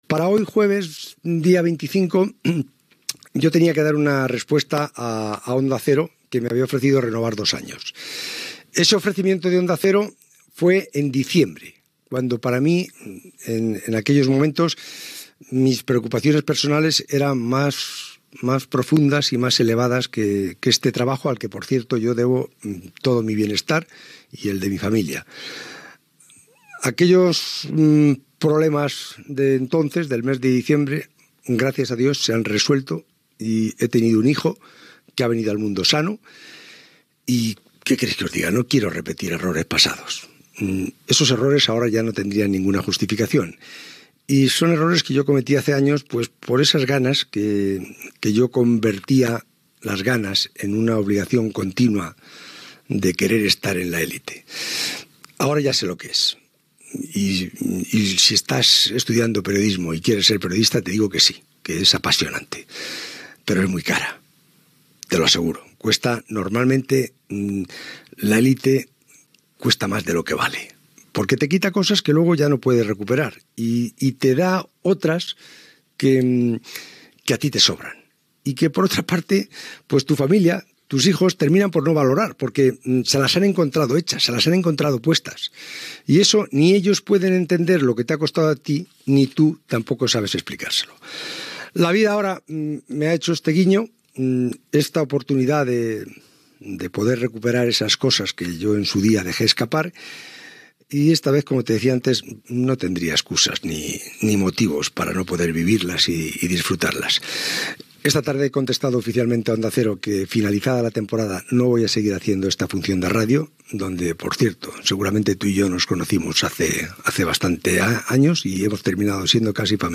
José Ramón de la Morena anuncia que deixarà de fer el programa al final de la temporada, en haver tingut un fill i voler-se dedicar a la seva família.
Esportiu